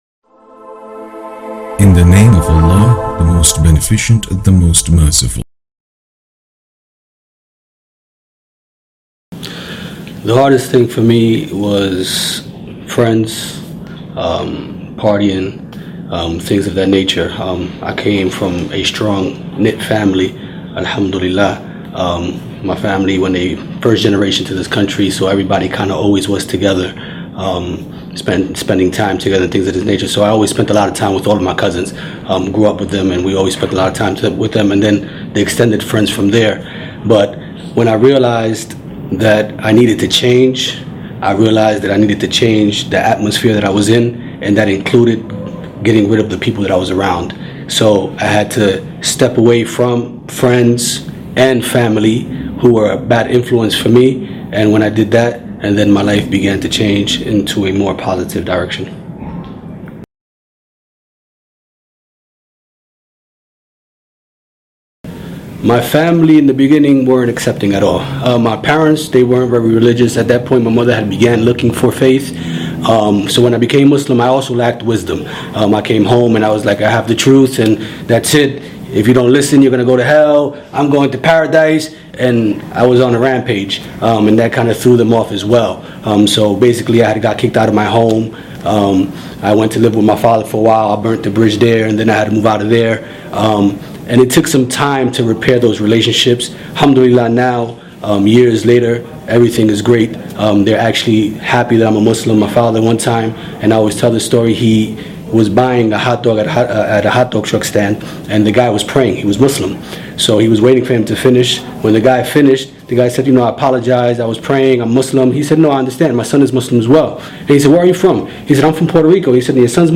877-Why-Islam presents an interview with a revert who advises everyone searching for the Truth to study religion’s core teachings and foundation like who/what they worship?